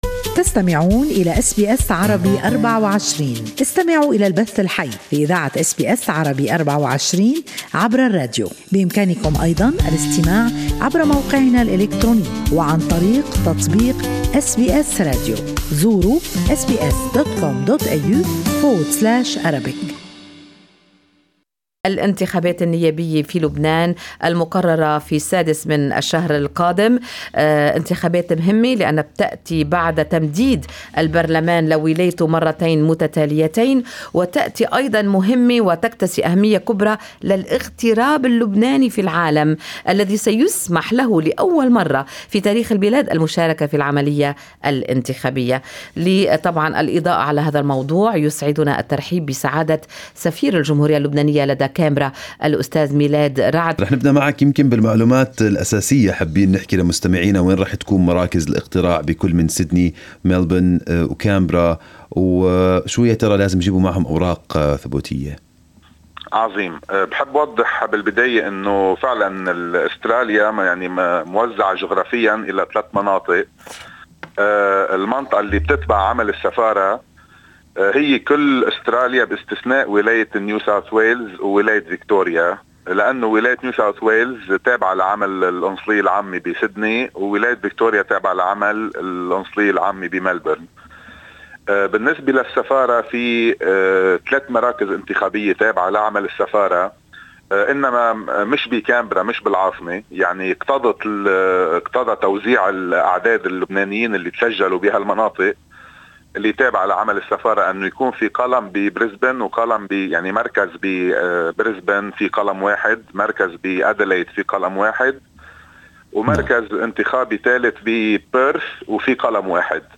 Good Morning Australia interviewed Ambassador of Lebanon, Milad Raad to discuss the upcoming parliamentary election and the preparations in Australia that's home to the largest number of expat voters at 11,826 people.